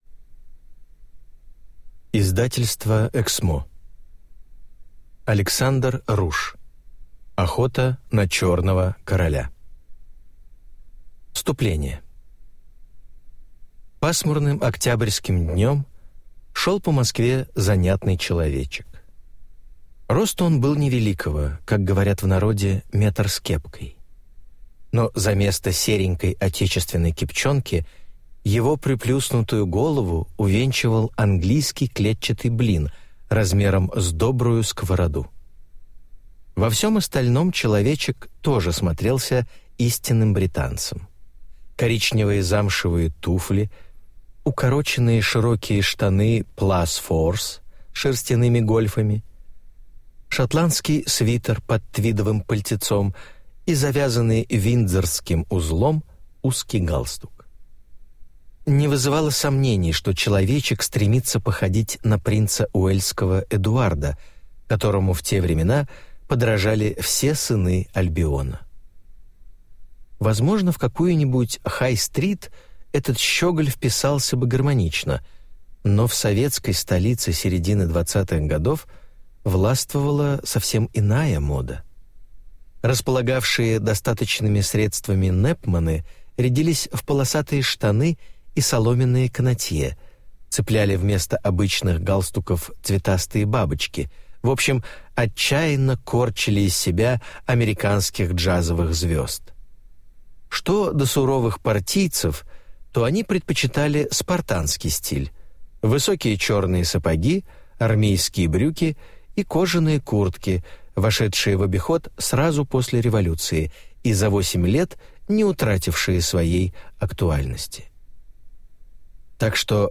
Аудиокнига Охота на черного короля | Библиотека аудиокниг